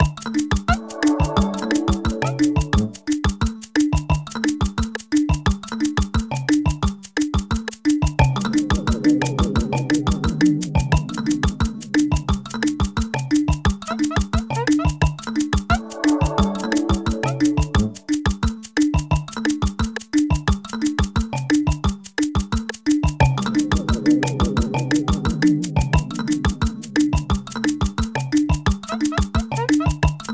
Description Music
Source Soundtrack